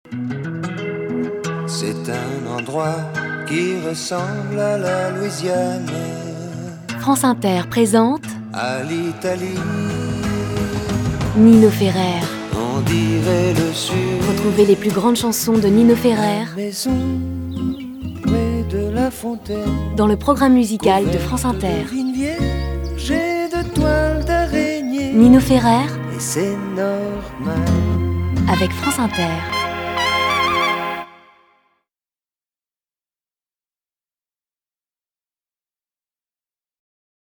Calme
Doux
Posé
Souriant
Voix off
Pub Radio